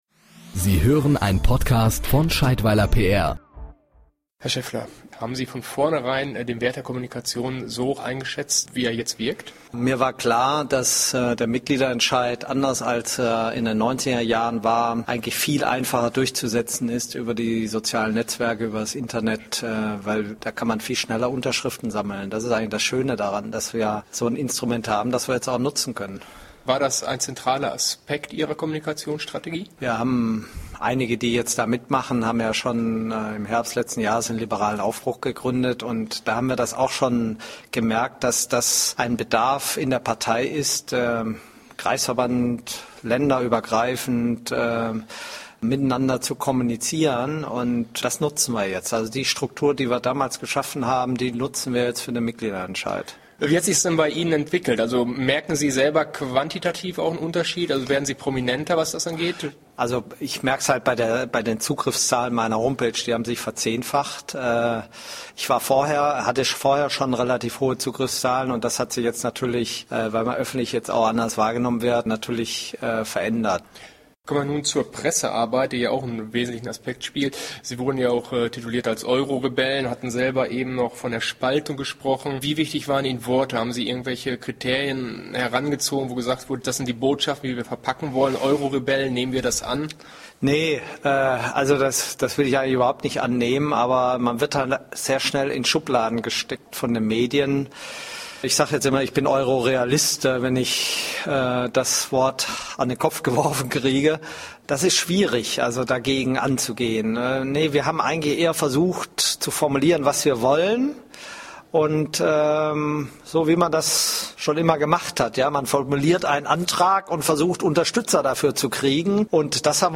Interview mit Frank Schäffler: Kommunikation zum FDP Euro-Mitgliederentscheid